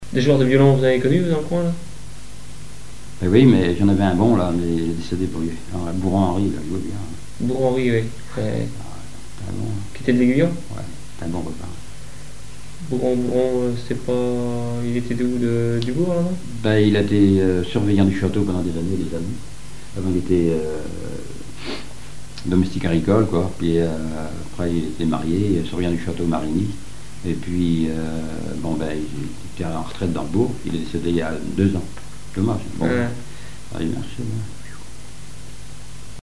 Témoignage